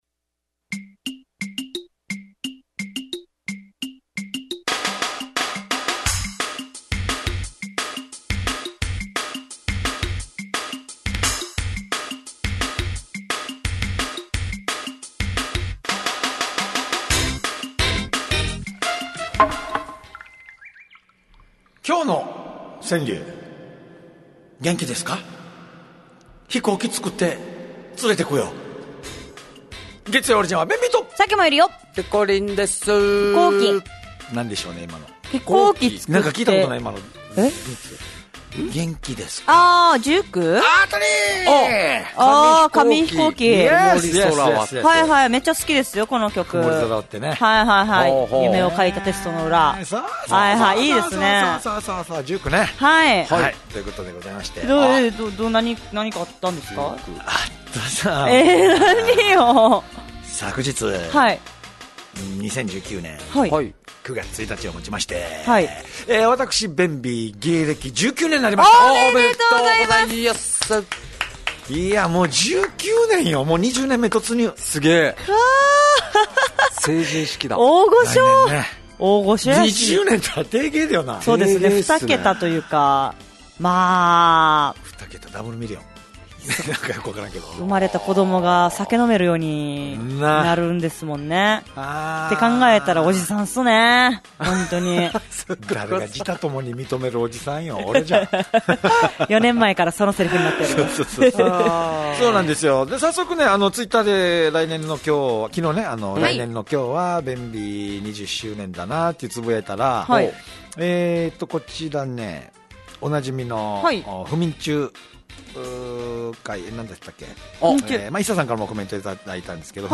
fm那覇がお届けする沖縄のお笑い集団・オリジンメンバー出演のバラエティ番組のオリジンアワー